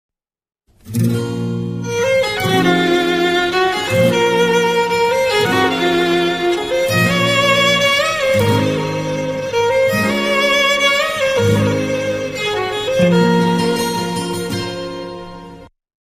ملودی تکنوازی ساز